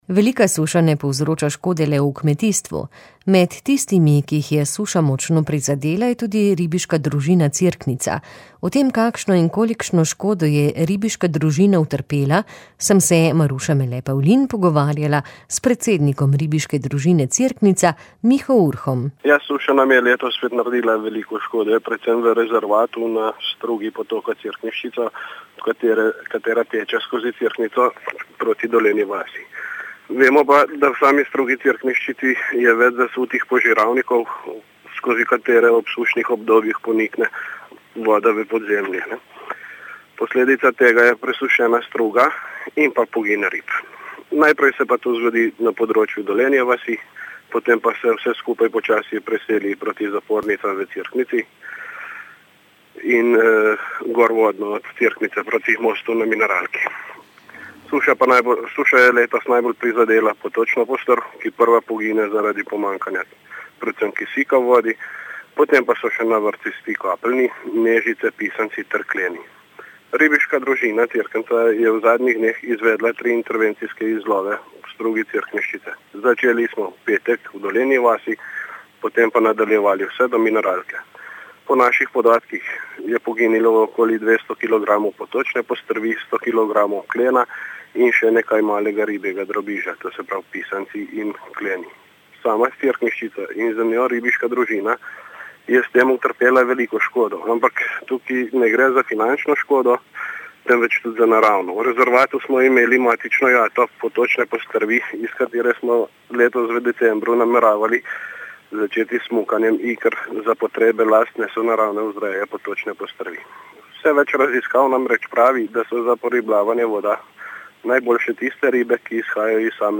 • novice radio94 r94